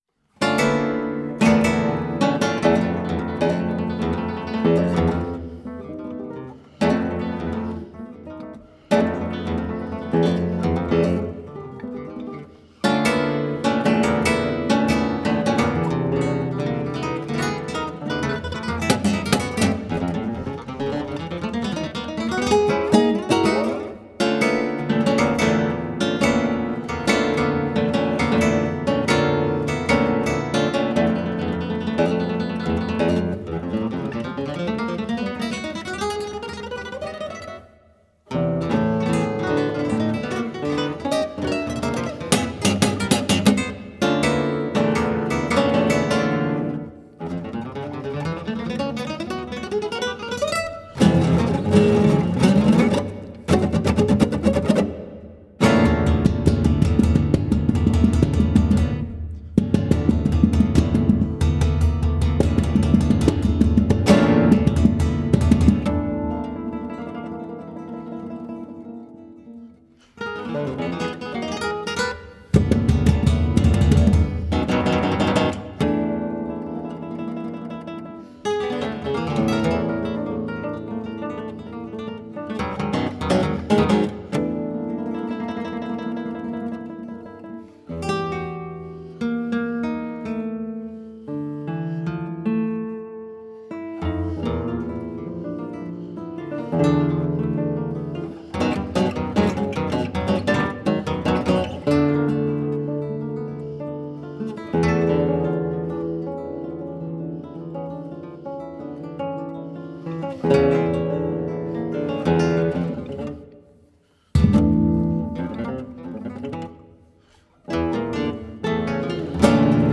per due chitarre / for two guitars (2011)
Guitar